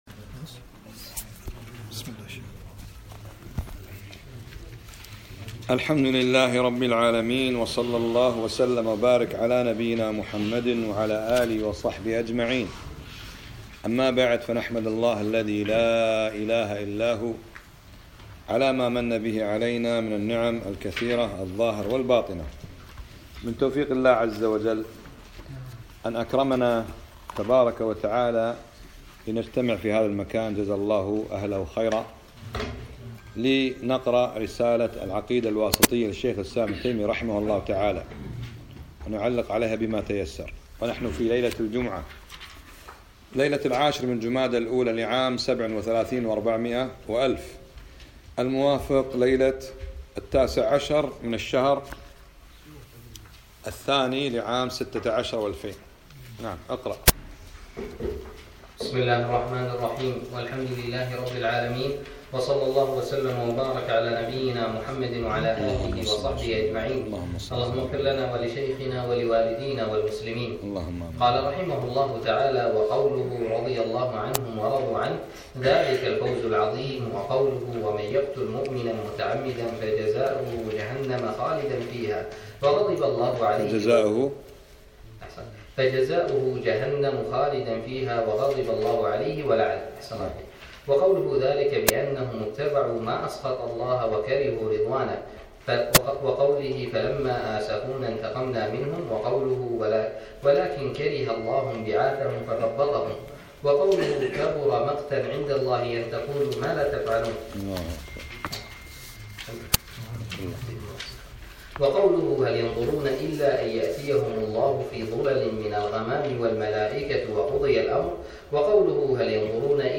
الدرس السادس - متن العقيدة الواسطية